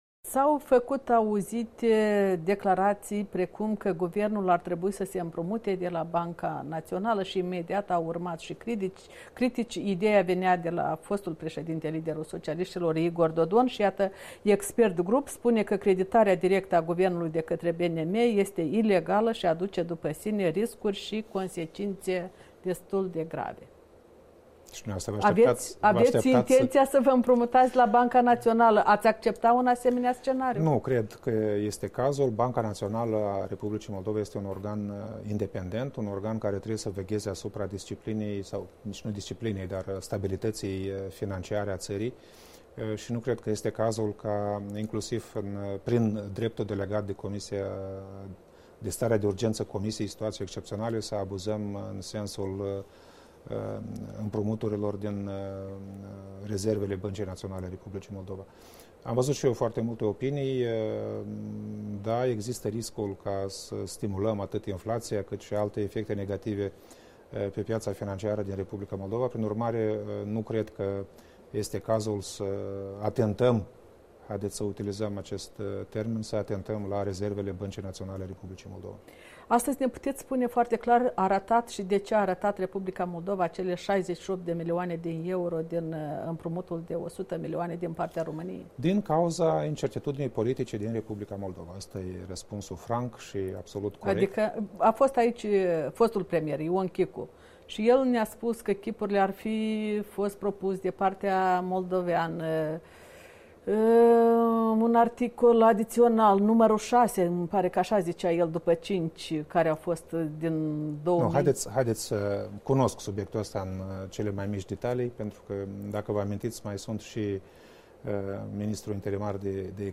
Interviu cu premierul Aureliu Ciocoi (II)